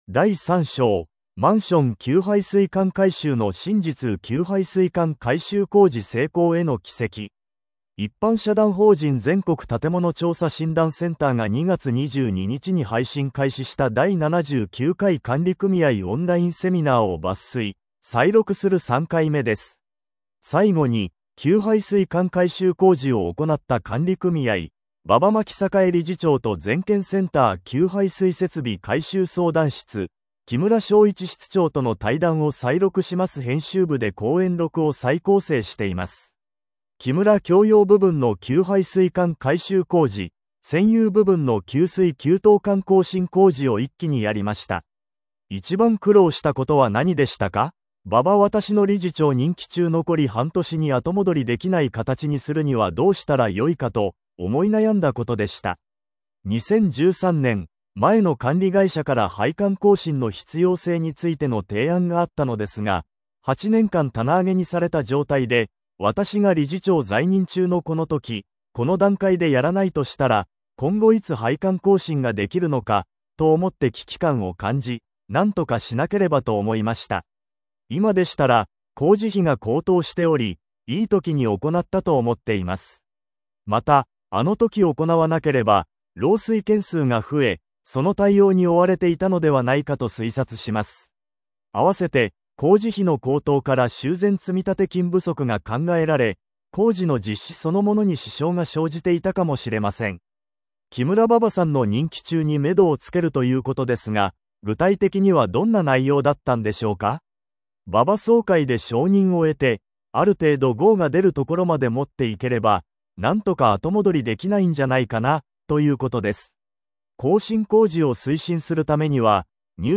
第79回管理組合オンライン・セミナー採録 ＜インタビュー＞『マンション給排水管改修の真実～給排水管改修工事 成功への軌跡』